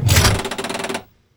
controlStick1.wav